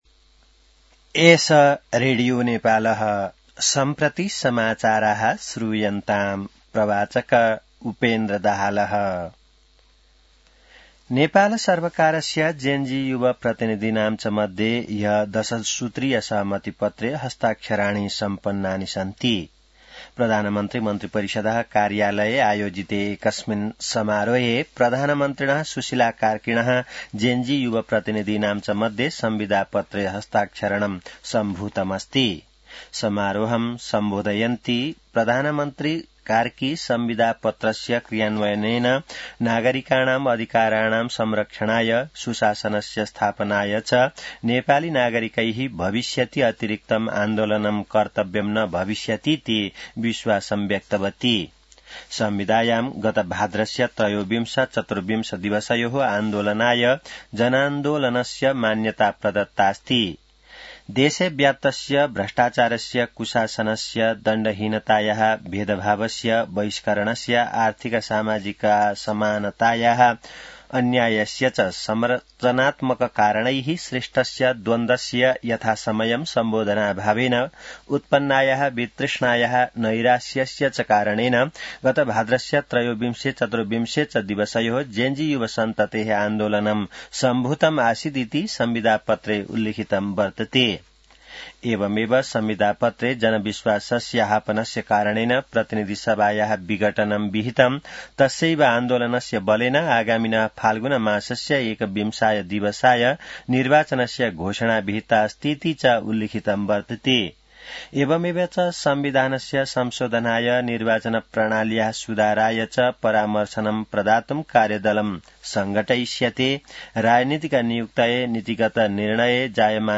संस्कृत समाचार : २५ मंसिर , २०८२